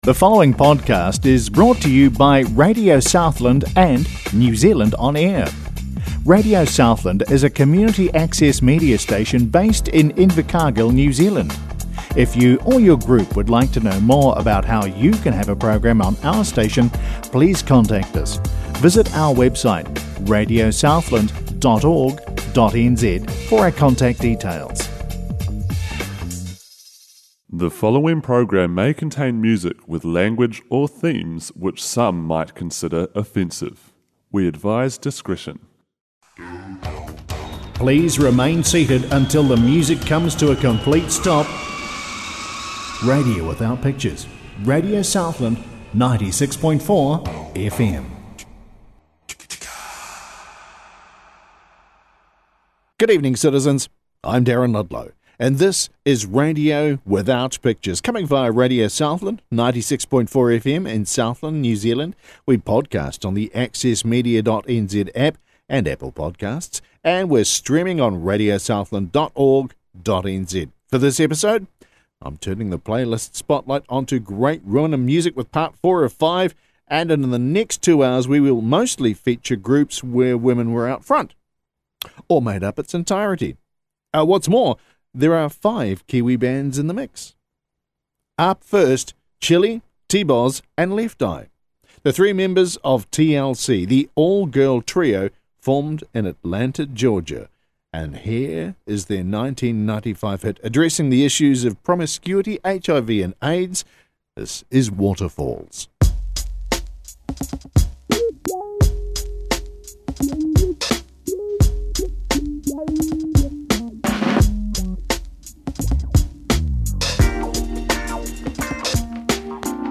classic tracks